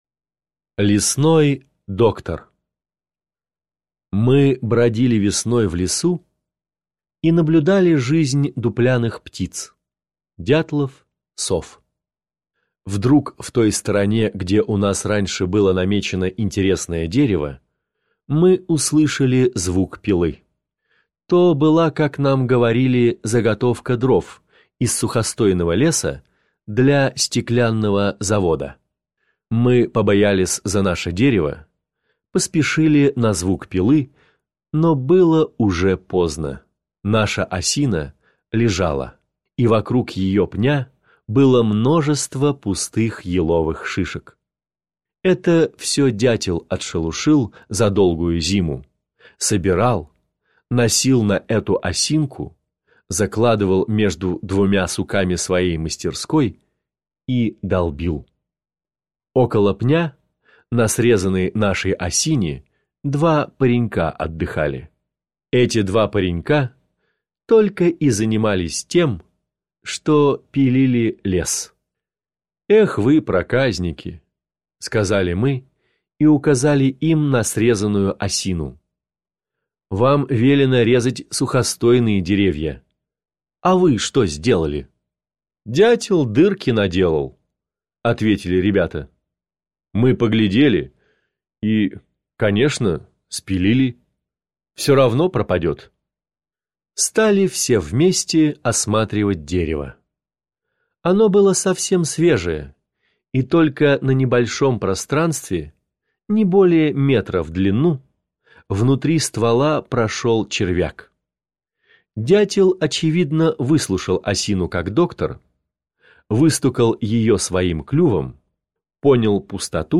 Лесной доктор – Пришвин М.М. (аудиоверсия)
Аудиокнига в разделах